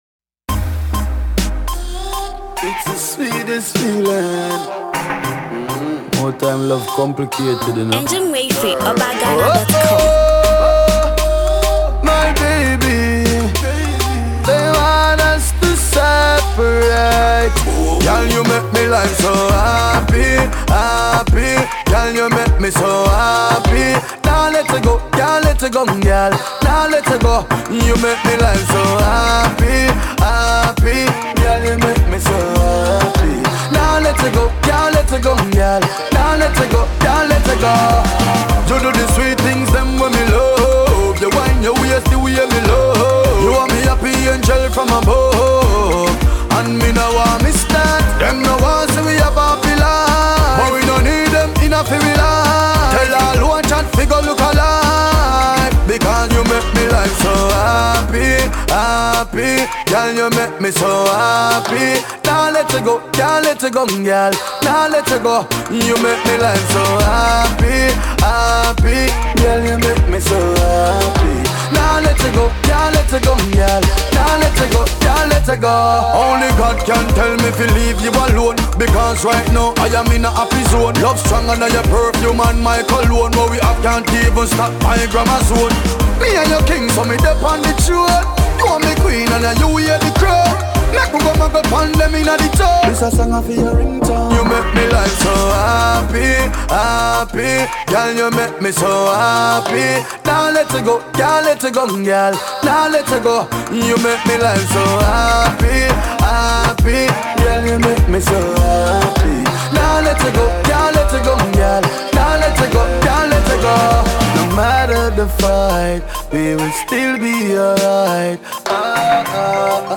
Reggae/Dancehall